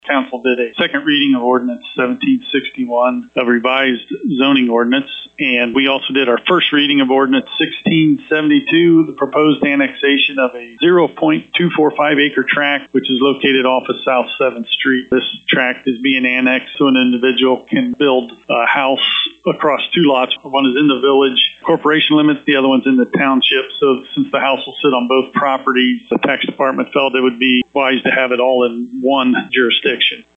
WCSM Headline News
For a summary with Coldwater Village Manager Eric Thomas: